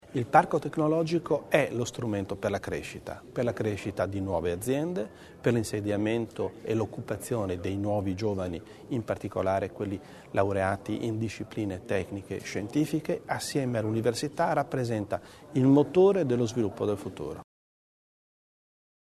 L¿Assessore Bizzo sulle priorità per il parco tecnologico